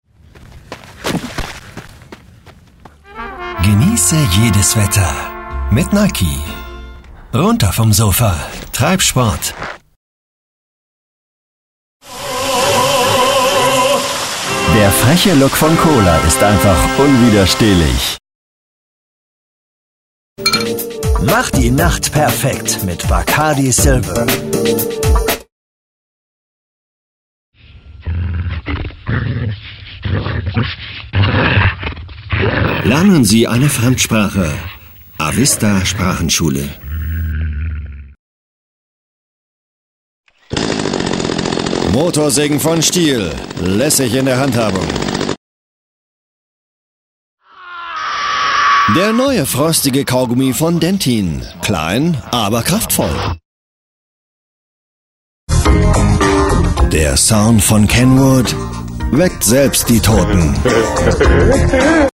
deutscher Profi Sprecher für Werbung, Kommentar, Imagefilme, Podcast, Multimedia, Lernsoftware, E-learning, Spiele uva.
Sprechprobe: Industrie (Muttersprache):